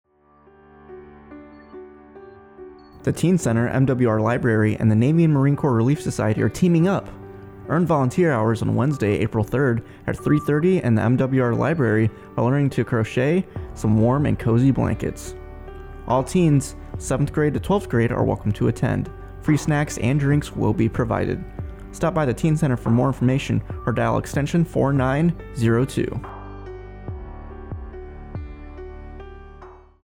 spotAFNBahrainRadioMWR